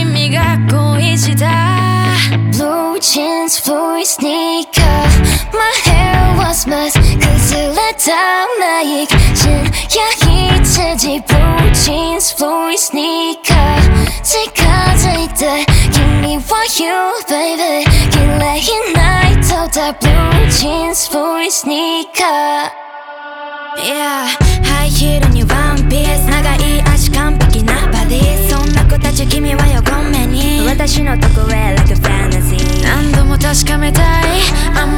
J-Pop
2025-07-14 Жанр: Поп музыка Длительность